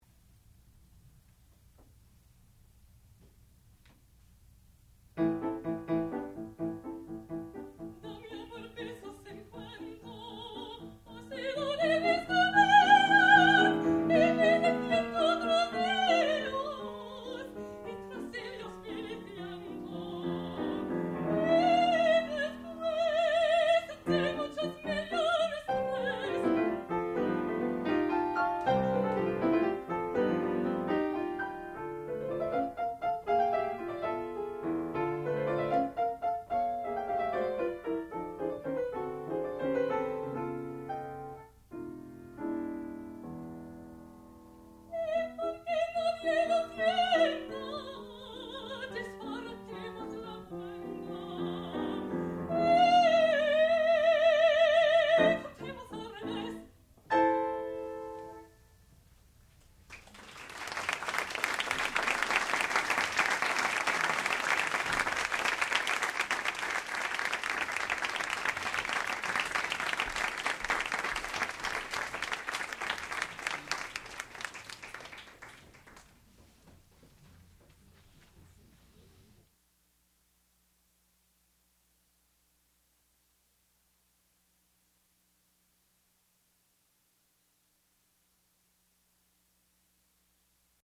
sound recording-musical
classical music
piano
mezzo-soprano
Master's Recital